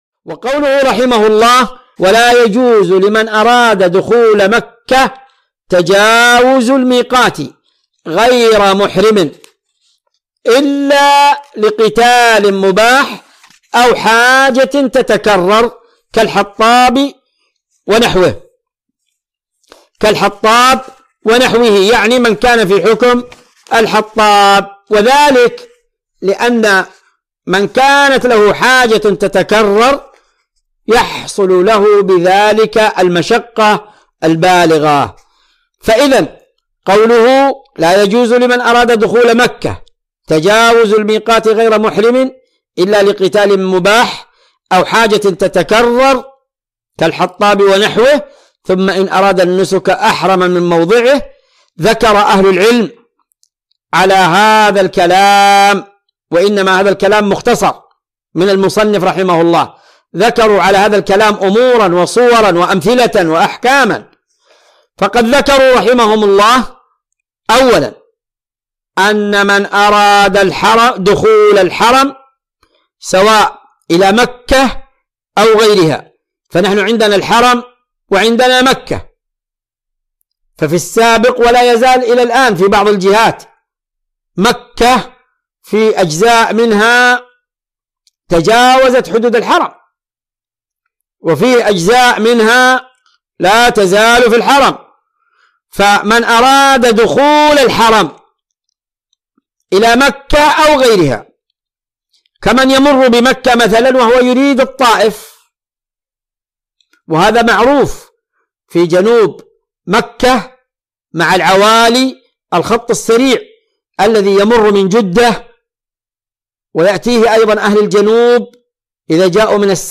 مقتطف من شرح كتاب الحج من عمدة الفقه الشريط الثالث .